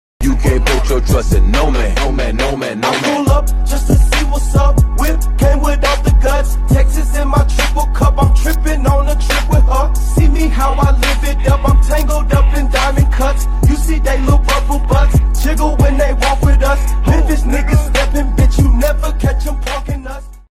Better one tiktok messes with the quality so badly